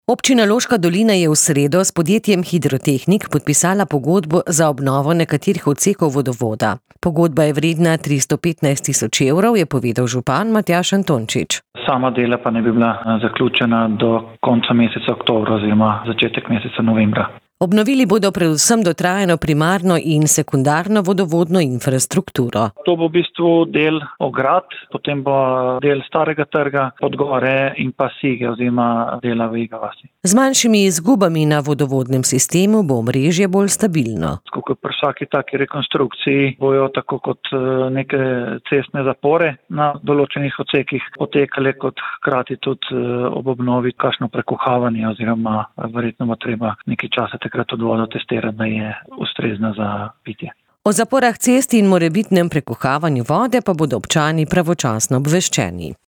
Občina Loška dolina je v sredo s podjetjem Hidrotehnik podpisala pogodbo za obnovo nekaterih odsekov vodovoda. Pogodba je vredna 315 tisoč evrov, je povedal župan Matjaž Antončič.